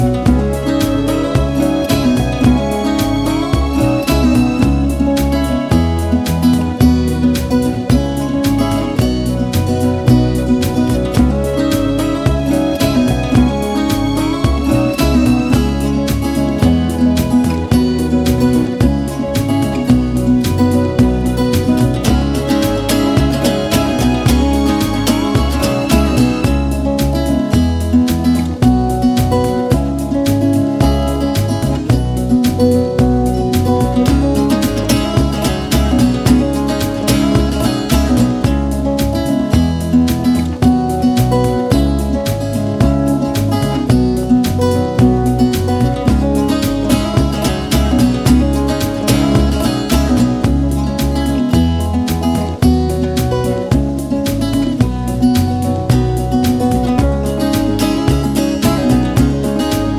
Country (Female)